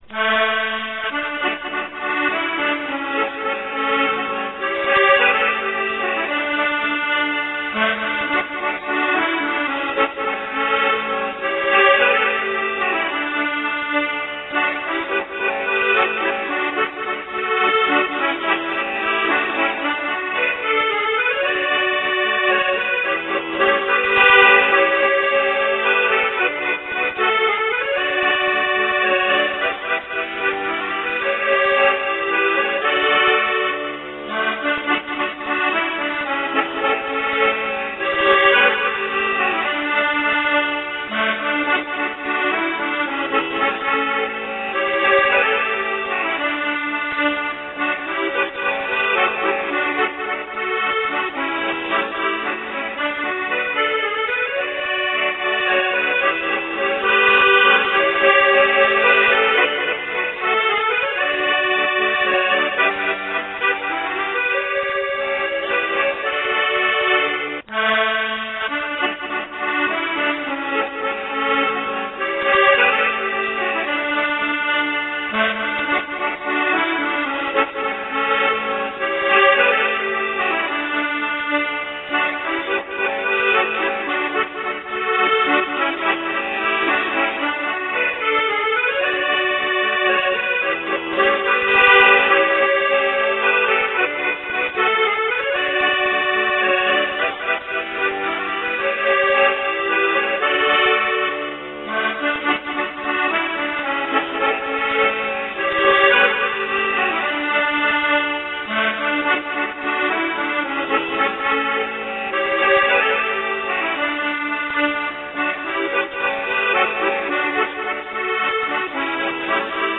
German Folksongs
Süddeutsche Volksweise